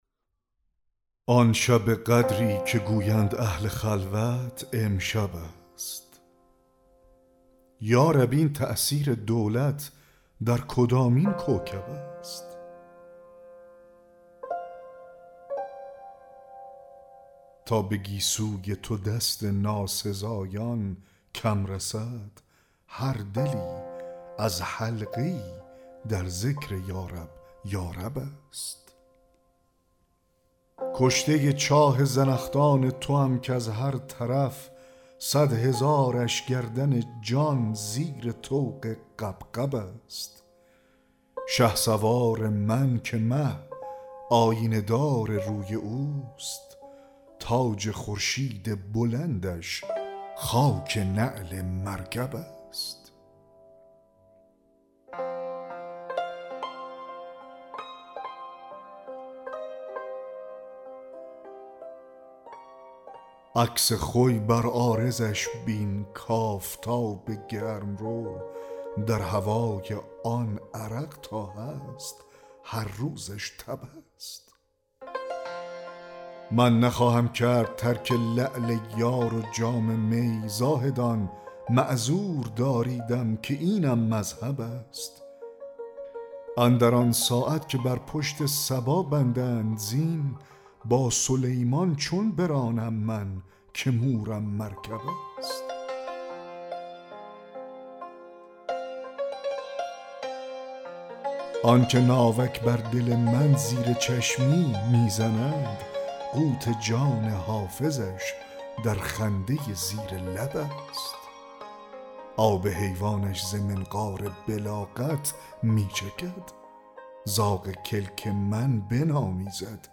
دکلمه غزل 31 حافظ